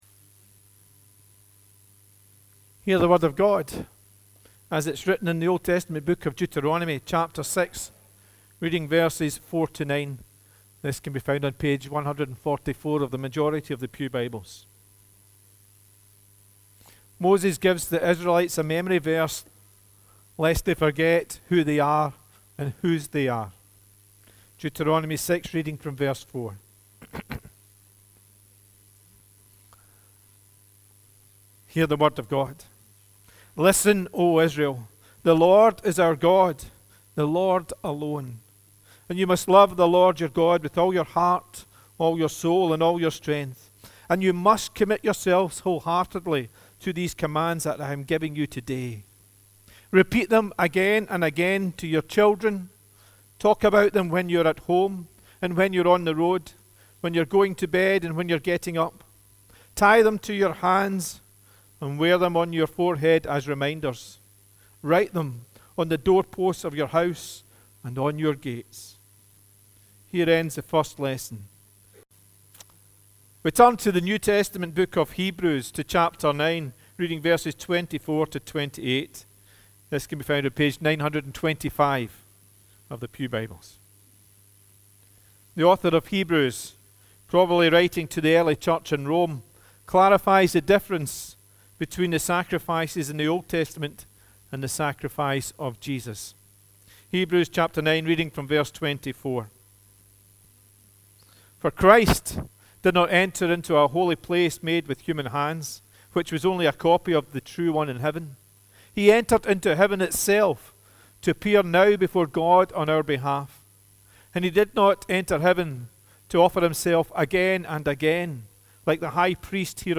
Remembrance Day - Uddingston Burnhead Parish Church